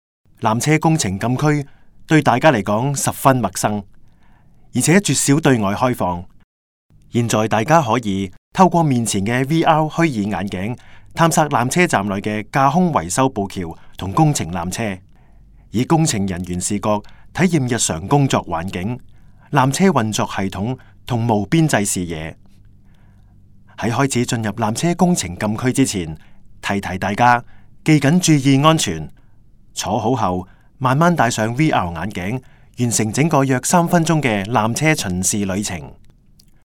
纜車探知館語音導賞 (廣東話)